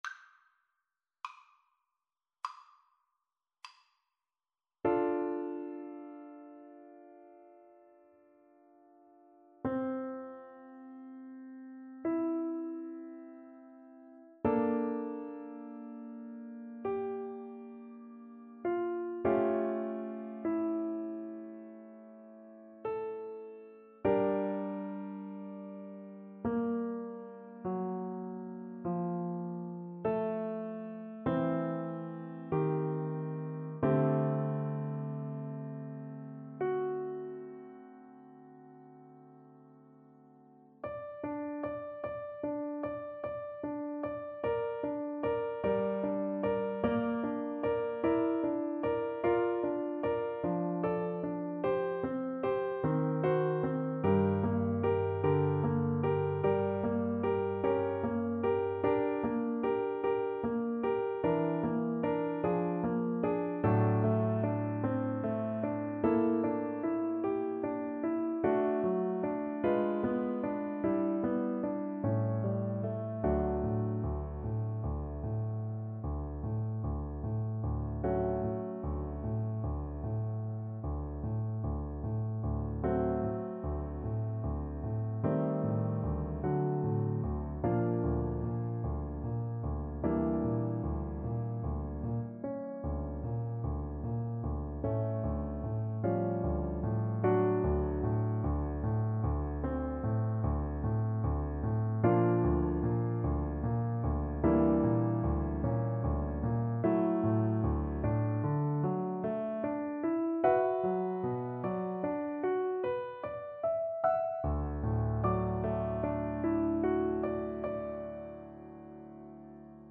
Play (or use space bar on your keyboard) Pause Music Playalong - Piano Accompaniment Playalong Band Accompaniment not yet available reset tempo print settings full screen
D minor (Sounding Pitch) A minor (French Horn in F) (View more D minor Music for French Horn )
~ = 100 Lento =50
Classical (View more Classical French Horn Music)